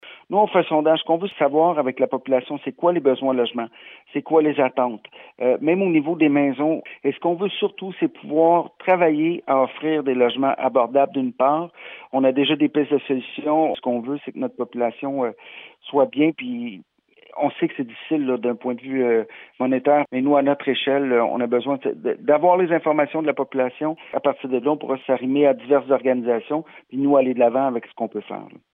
Patrick Melchior, maire de Farnham.